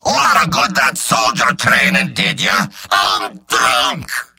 Robot-filtered lines from MvM. This is an audio clip from the game Team Fortress 2 .
{{AudioTF2}} Category:Demoman Robot audio responses You cannot overwrite this file.